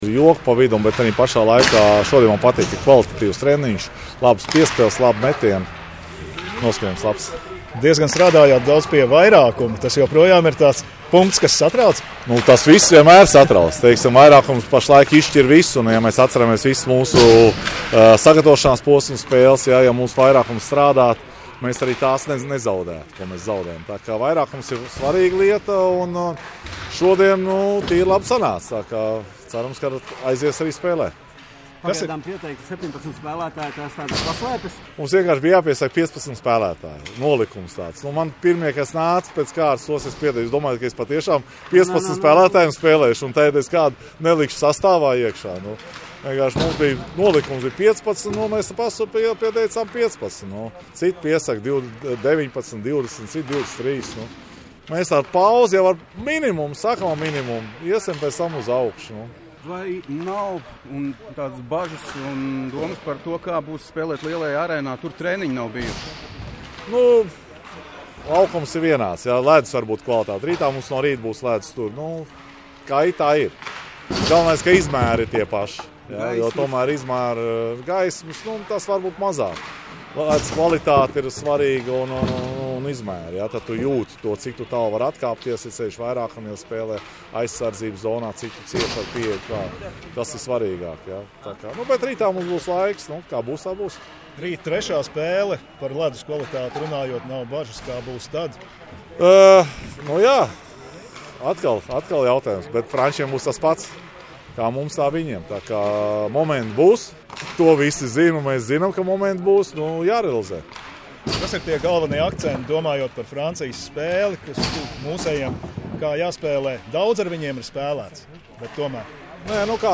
Kā komentēja valstsvienības galvenais treneris Harijs Vītoliņš, bija joki, bet tajā pašā laikā bija kvalitatīvs treniņš – labas piespēles, labi metieni.